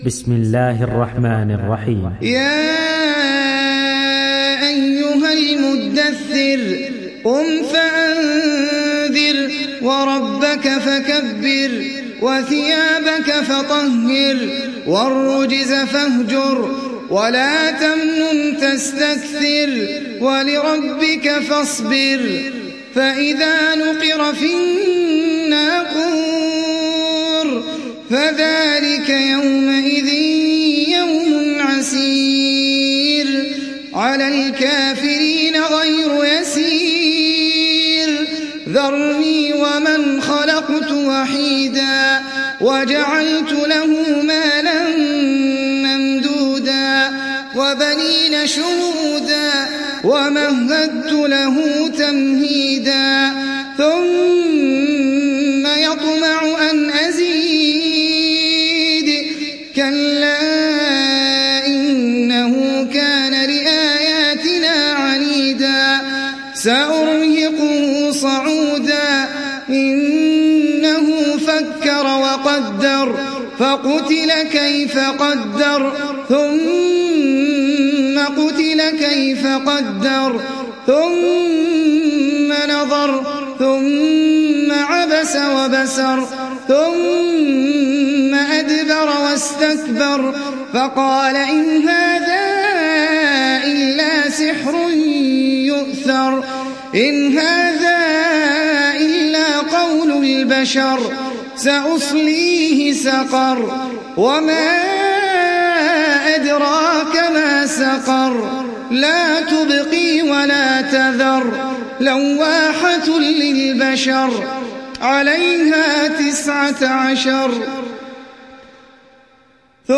تحميل سورة المدثر mp3 بصوت أحمد العجمي برواية حفص عن عاصم, تحميل استماع القرآن الكريم على الجوال mp3 كاملا بروابط مباشرة وسريعة